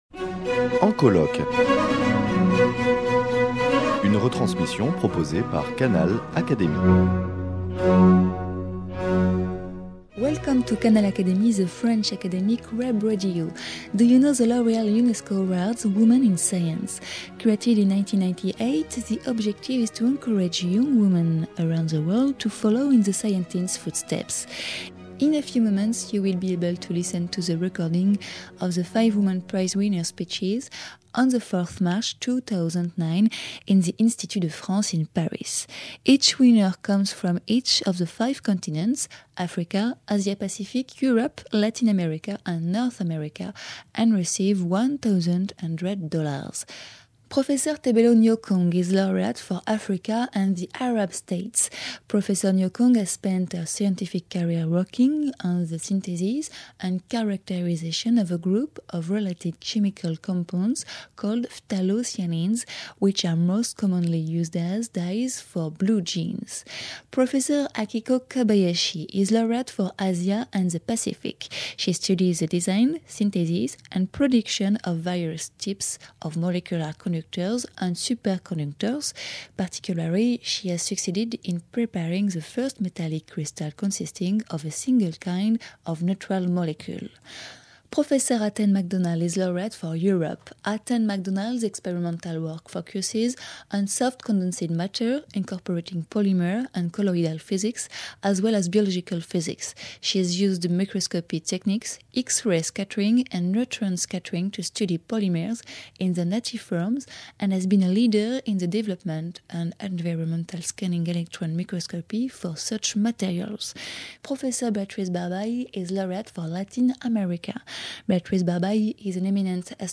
Five women researchers in life sciences received L’OREAL-UNESCO Awards for Women in Science. The ceremony took place at the Institut de France in March 2009.